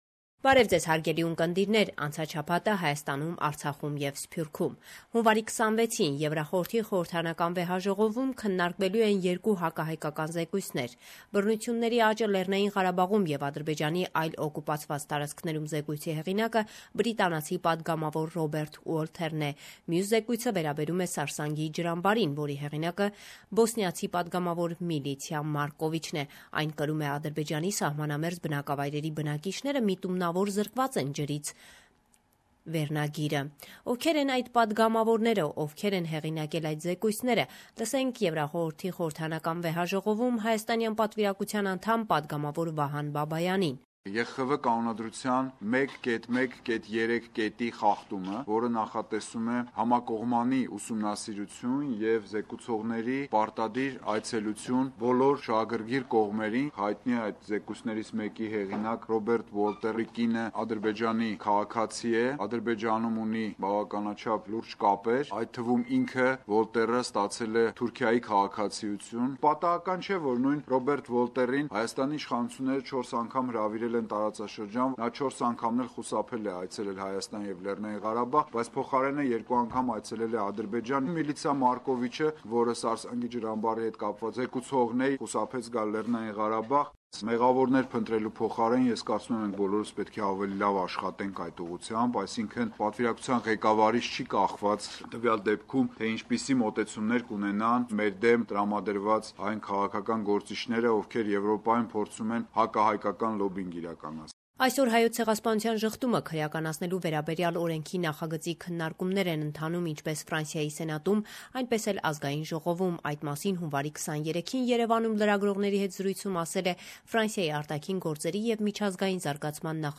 Latest news - 26/1/2016